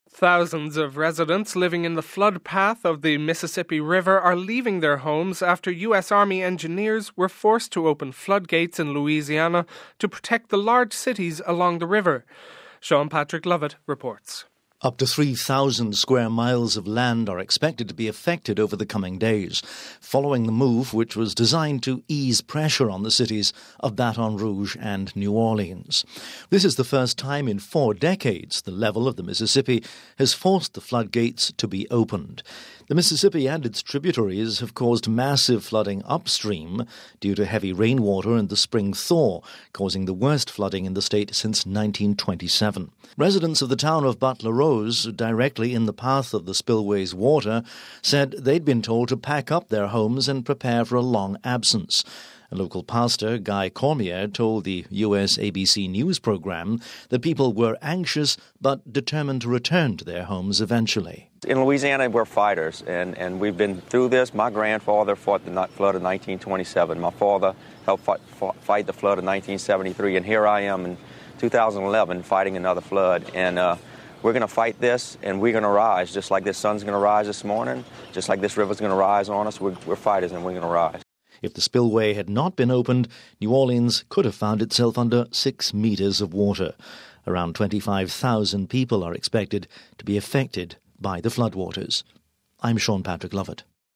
report